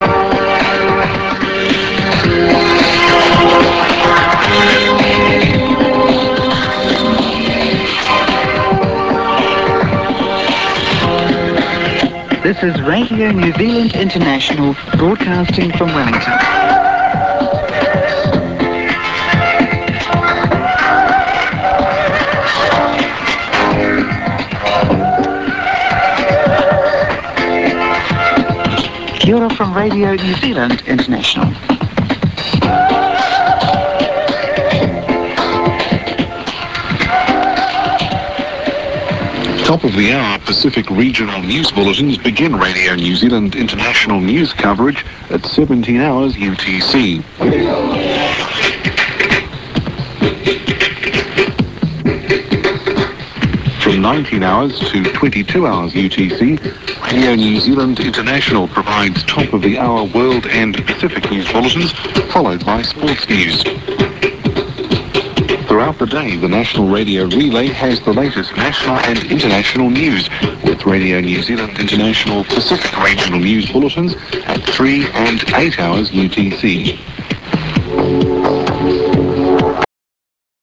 The audio sample links provided above are through the Internet, which generally provides a much better sound than an actual shortwave broadcast. –
This segment from Radio New Zealand International was taken off the air and provides a more realistic shortwave listening experience.